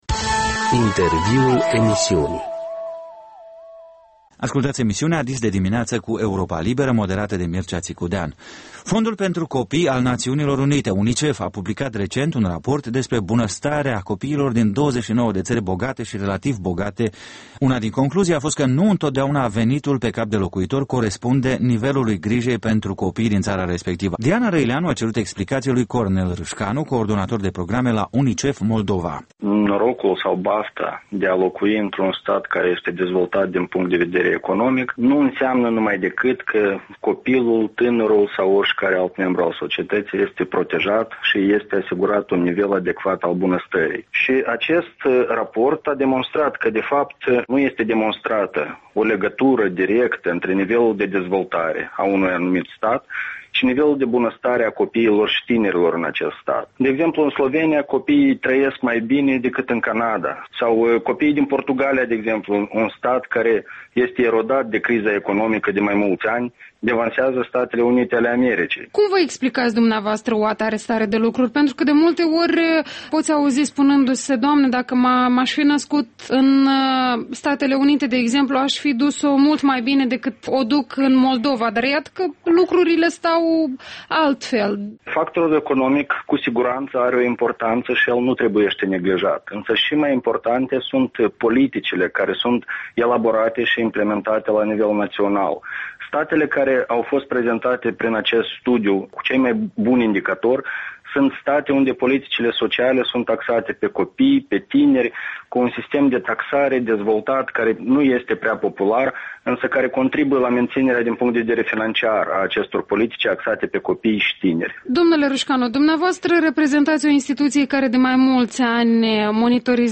Un interviu acordat Europei Libere